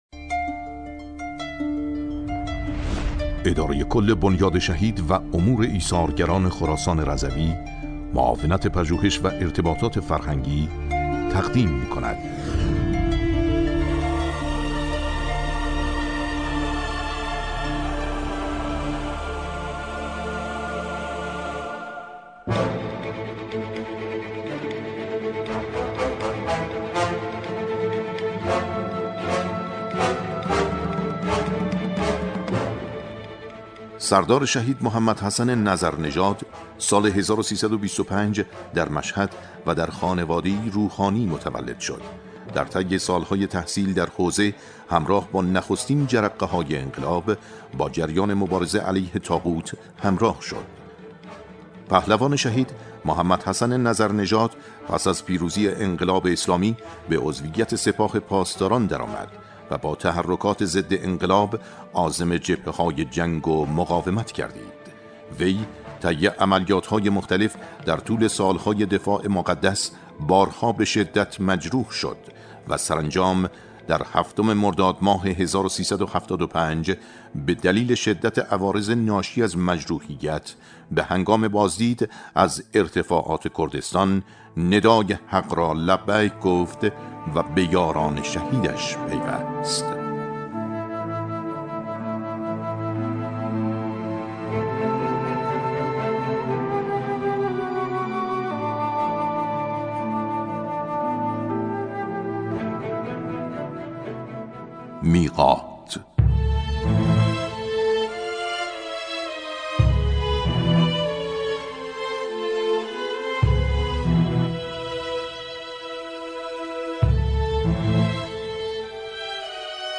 نمایشنامه رادیوبی میعاد